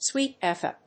/swɪit ɛf.eɪ(英国英語)/